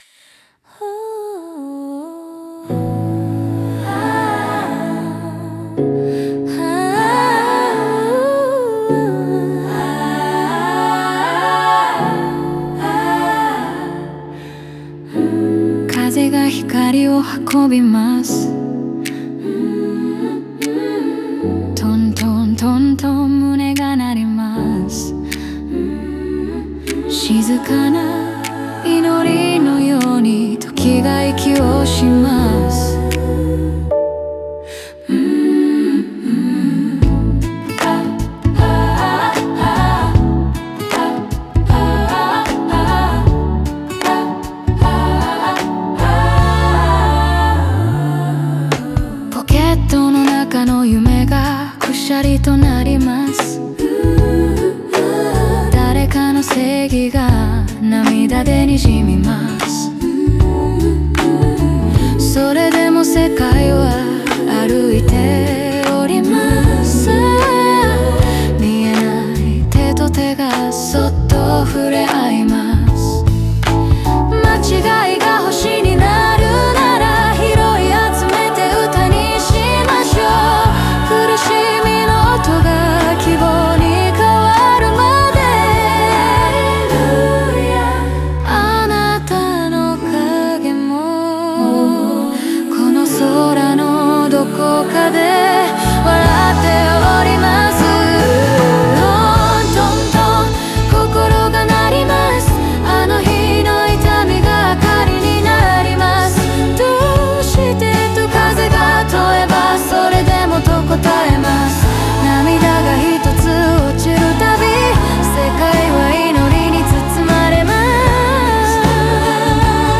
オリジナル曲♪
擬音語が感情のリズムを生み出し、賛美歌風のコーラスが祈りのように響くことで、個人の悲しみが普遍的な希望へと昇華していく。